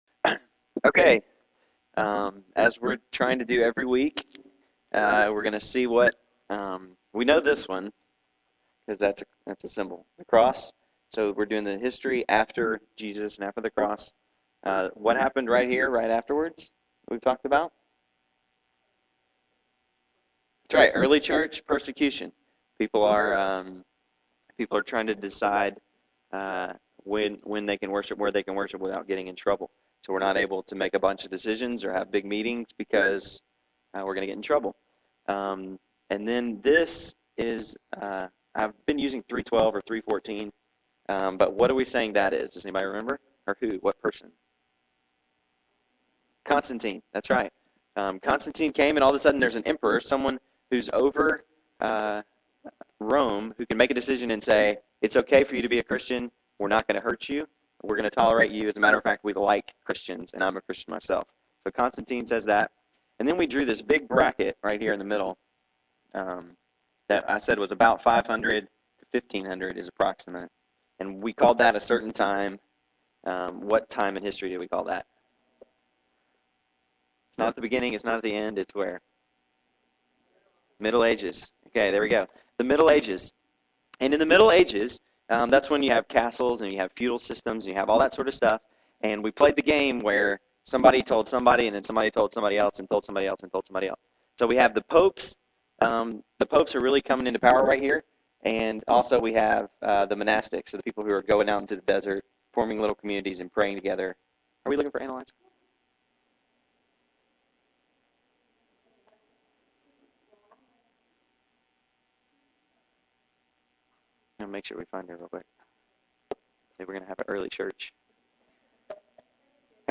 September 13, 2009 PM Worship Service | Vine Street Baptist Church